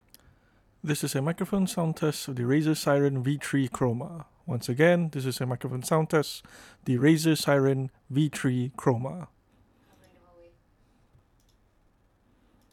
It’s no doubt a very attractive microphone and it still captures your voice well with a nice, warm tone.
Mic Test
We got a few recordings down with the Razer Seiren V3 Chroma to show how well it performs. Above here is just your standard recording in a quiet bedroom. It may not be a treated room but the pillows and carpeting should provide adequate material to reduce reverberations and diffuse sound better much better than an empty room.